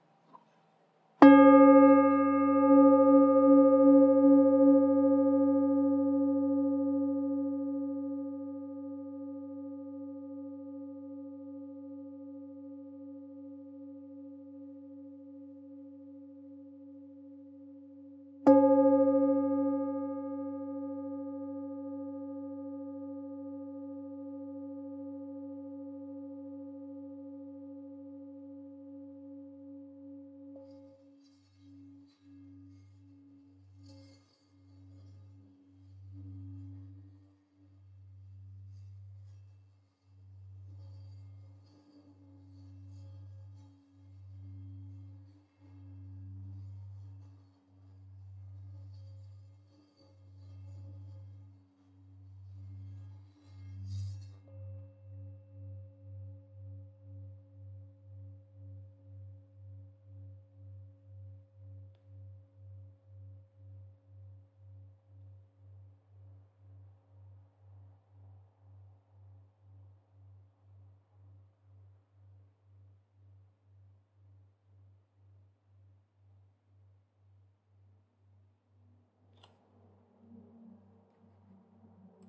Campana Tibetana ULTA Nota FA(F) 2 94 HZ -R002
Nota Armonica    DO(C) d4 283 HZ
Nota di fondo      FA(F) d2 94 HZ